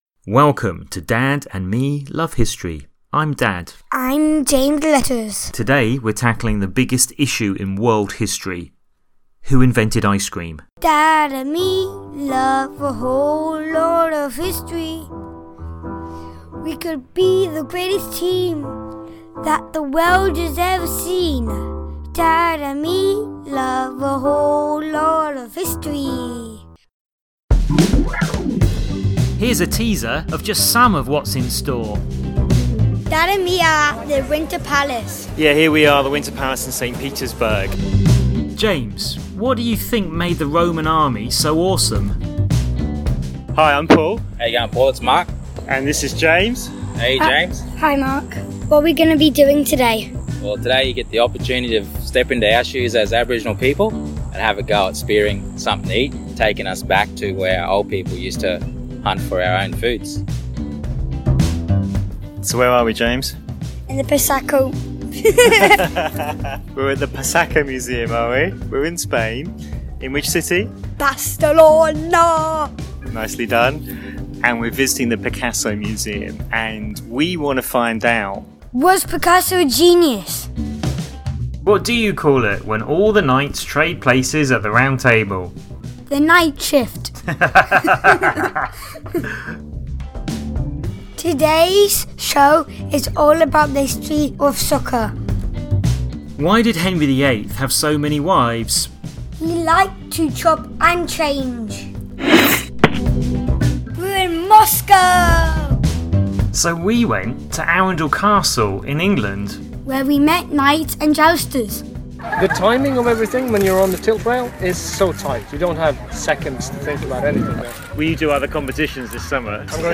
Get your kid away from the screen for 15 minutes of educational fun! Here’s a two-minute showreel of highlights: